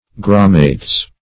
Search Result for " grammates" : The Collaborative International Dictionary of English v.0.48: Grammates \Gram"mates\, n. pl.